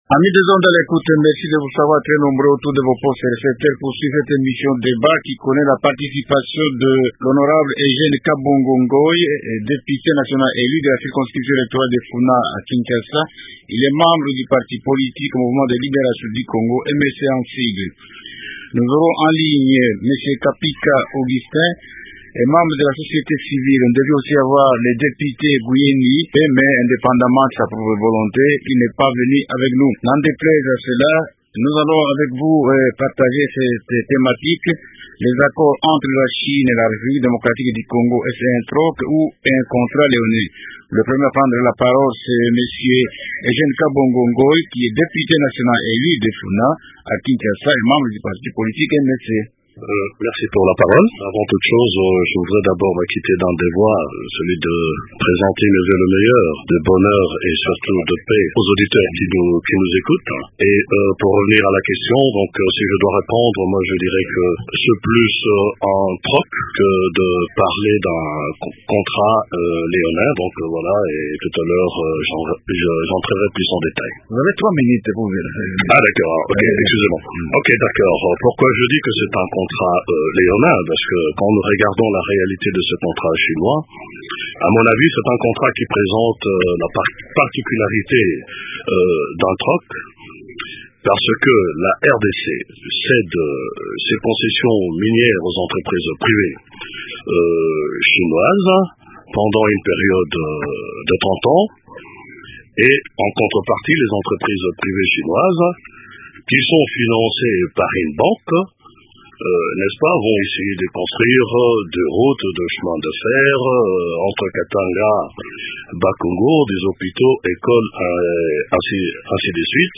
Les représentants de l’opposition et de la société civile réfléchissent autour de ce thème. rnInvités :